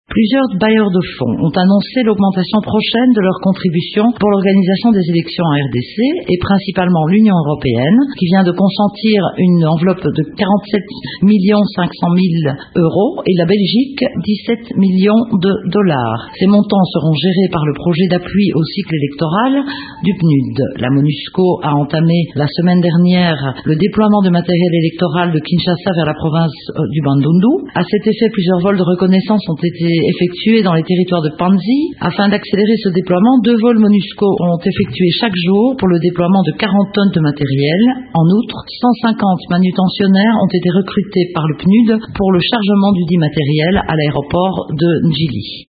Voici un extrait  de son adresse à la presse :